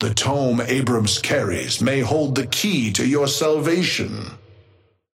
Amber Hand voice line - The tome Abrams carries may hold the key to your salvation.
Patron_male_ally_ghost_oathkeeper_5b_start_02.mp3